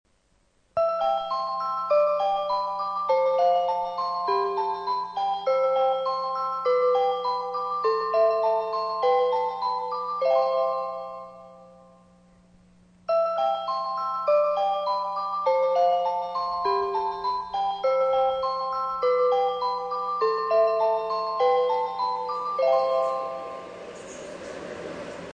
スピーカー：UNI-PEX LH-15T
音質：D
２番線接近メロディー（春） 下り・徳山方面 (123KB/25秒) ※下松で収録
山陽本線必殺頭切れです…　なんとかならないものでしょうか。